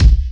SWKICK02.wav